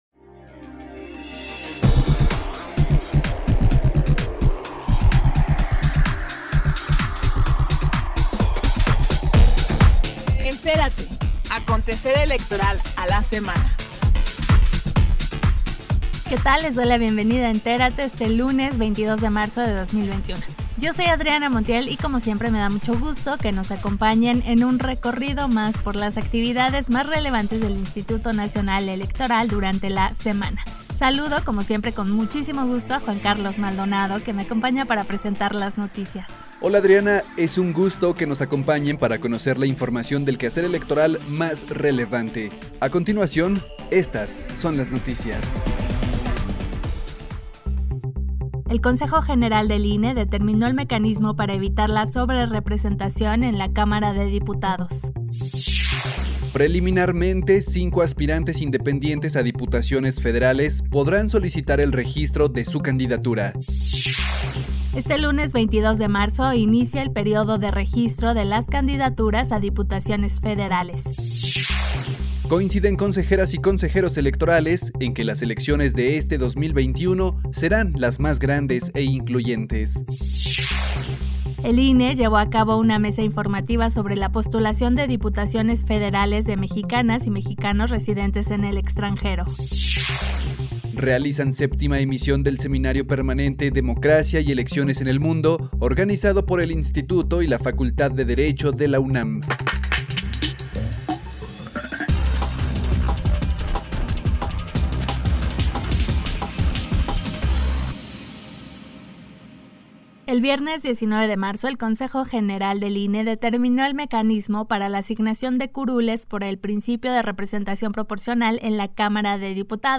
NOTICIARIO 22 DE MARZO 2021